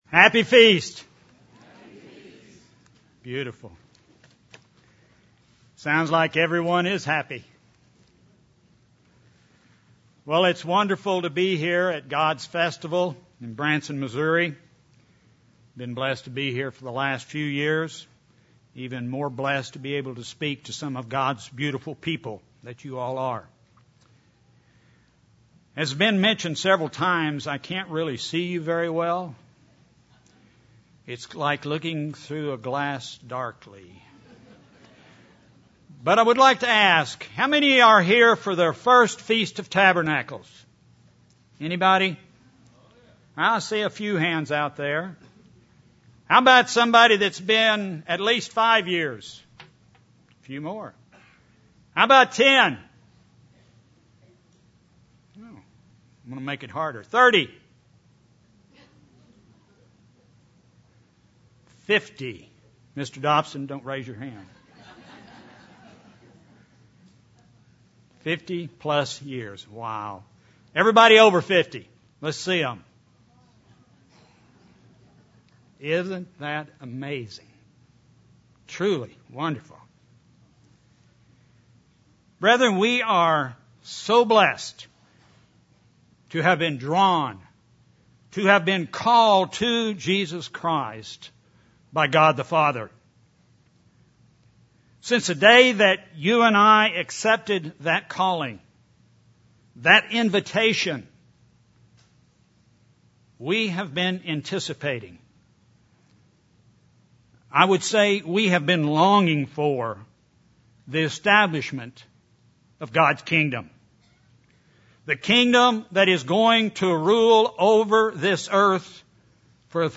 This sermon was given at the Branson, Missouri 2017 Feast site.